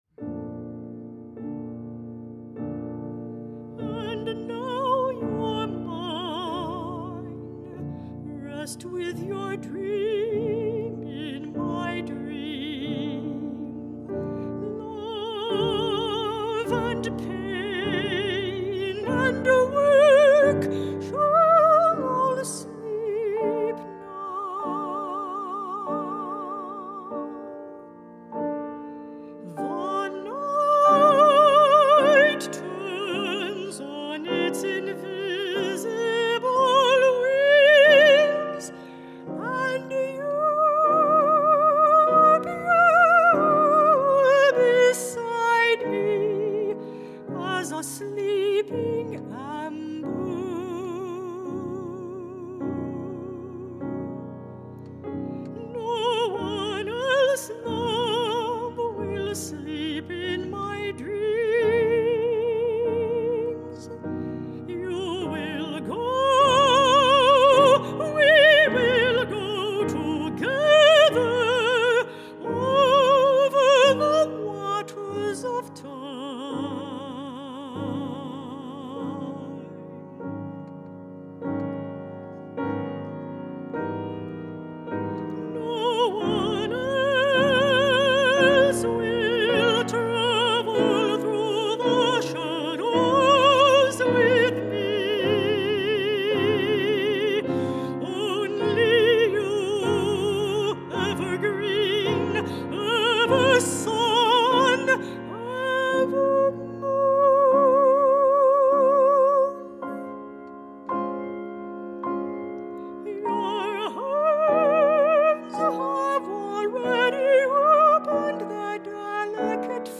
Soprano or Mezzo-Soprano & Piano (14′)